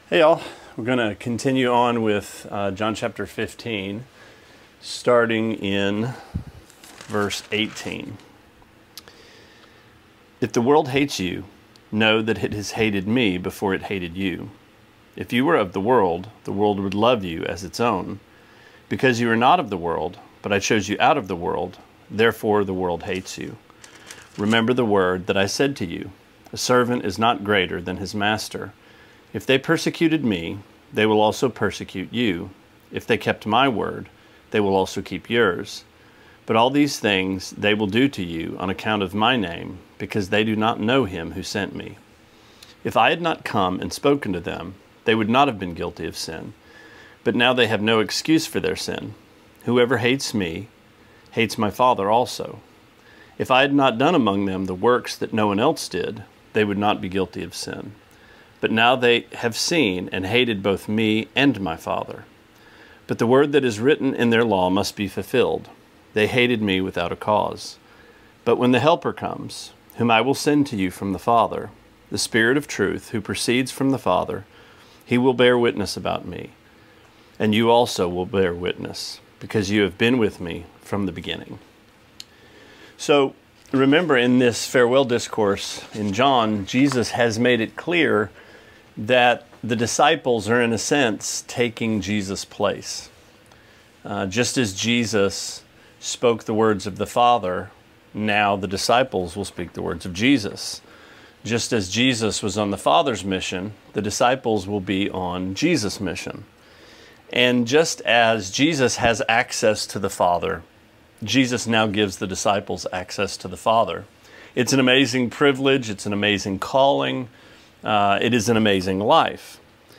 Sermonette 5/26: John 15:18-26: Opposition